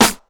07_Clap_10_SP.wav